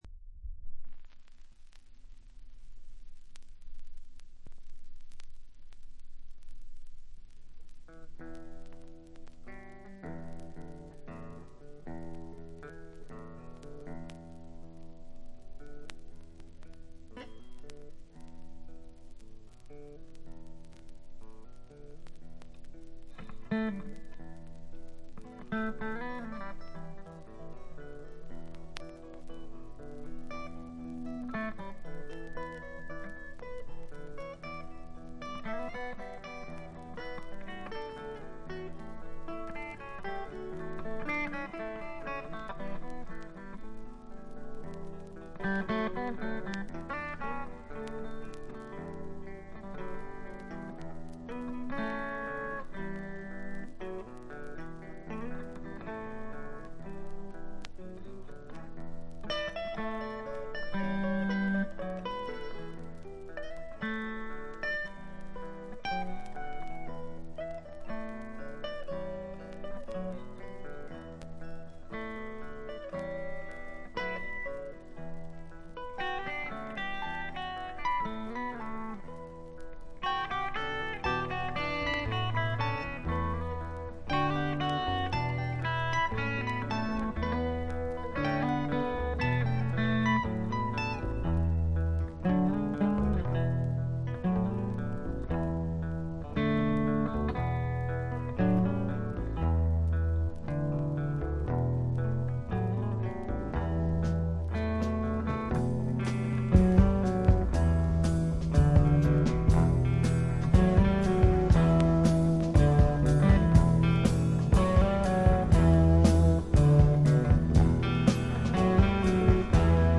全体に細かなチリプチが出ていますが、特に目立つノイズはなくA-寄りの良品です。
試聴曲は現品からの取り込み音源です。